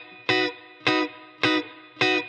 DD_TeleChop_105-Bmaj.wav